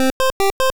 snd_walk.wav